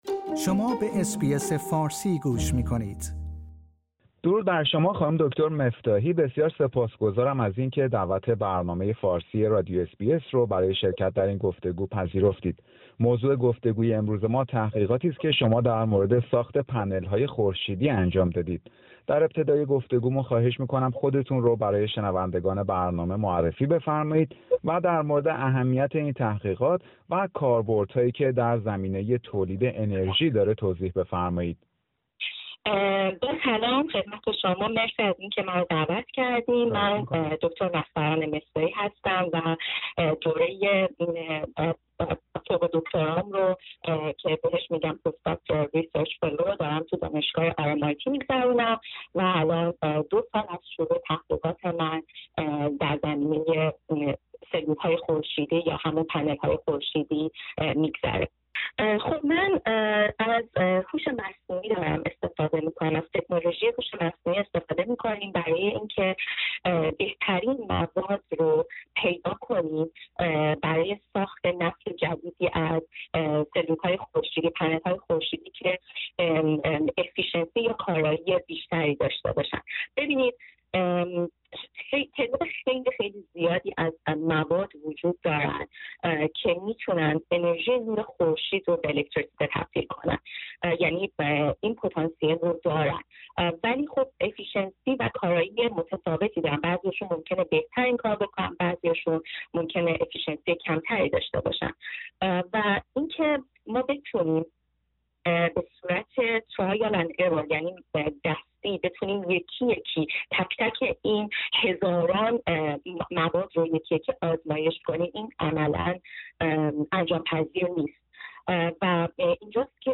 یک پژوهشگر دانشگاه آر ام آی تی (RMIT) مدل های کامپیوتری را ارائه می کند که می توانند به شناسایی بهترین مواد برای ساخت پنل های خورشیدی با کارایی بالا کمک کنند. برنامه فارسی رادیو اس بی اس در همین خصوص گفتگویی داشته